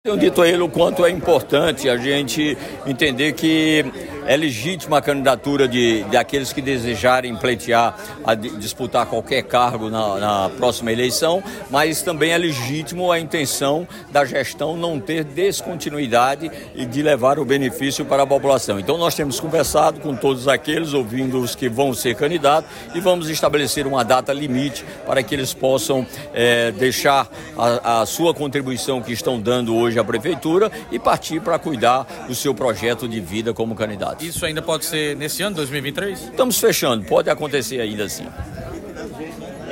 Abaixo a fala do prefeito Cícero Lucena, com exclusividade para o Portal PautaPB.